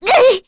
Requina-Vox_Damage_01_kr.wav